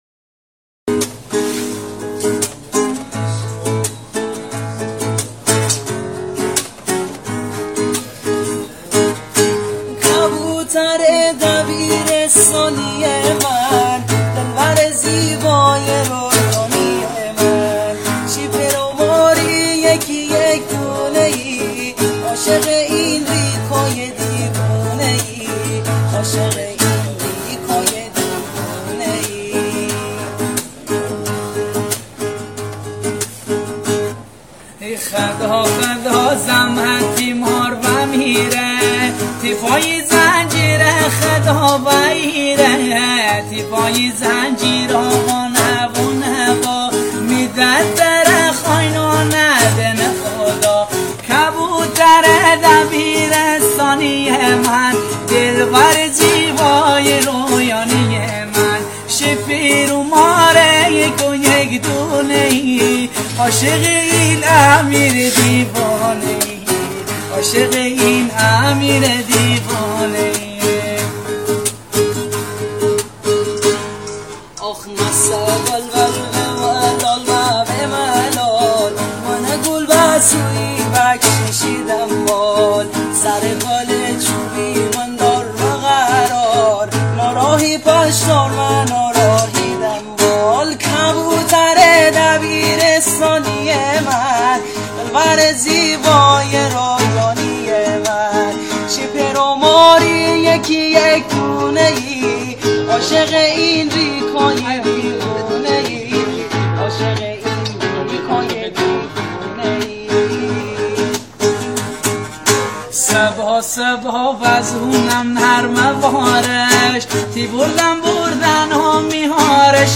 ترانه مازندرانی كبوتر دبيرستاني از
ورژن با گیتار